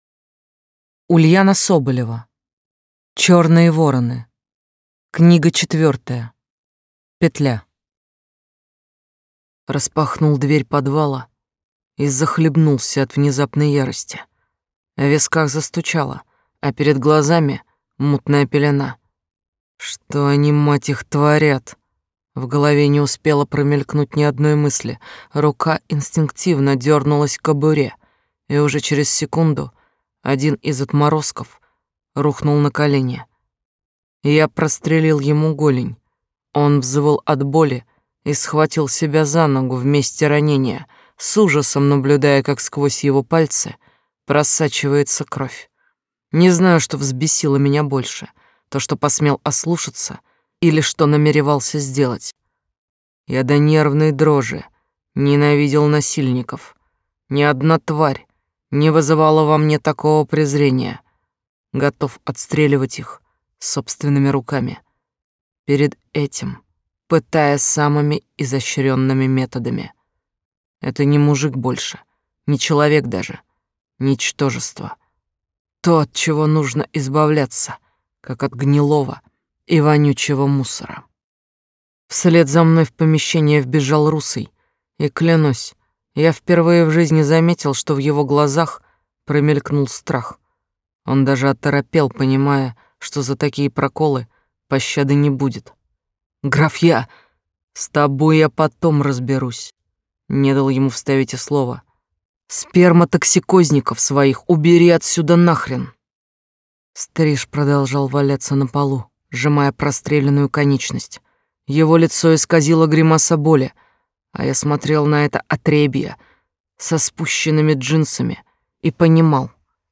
Аудиокнига Чёрные вороны. Книга 4. Петля | Библиотека аудиокниг